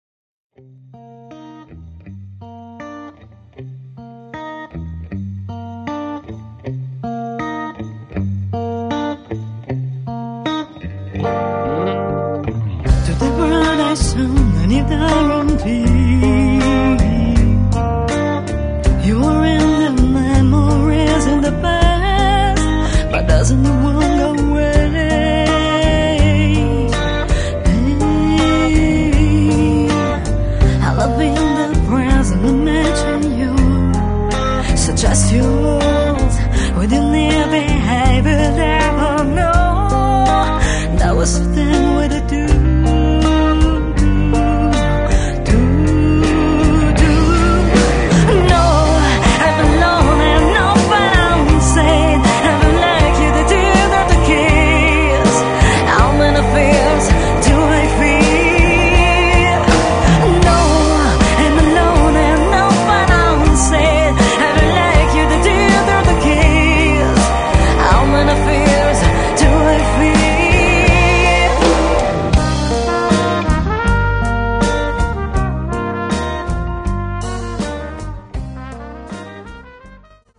Blues / Rock / Funk